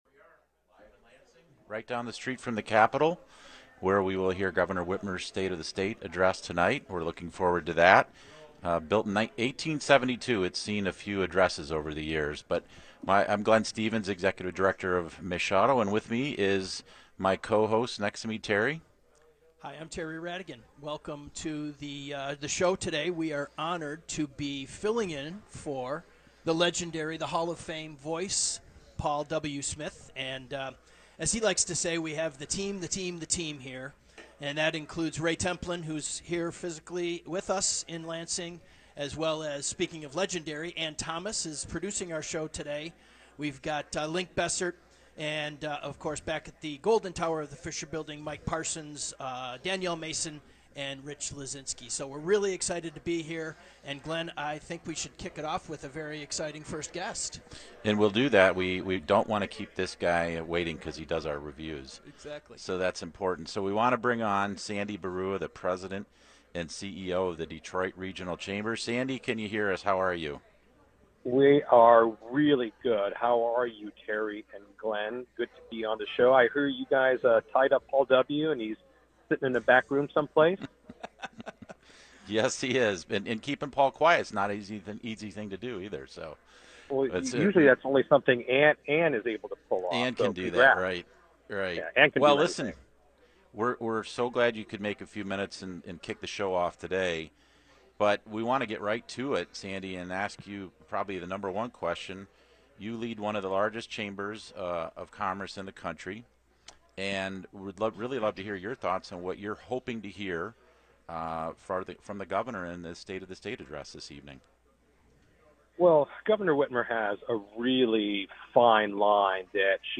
which broadcast live in Lansing prior to Gov.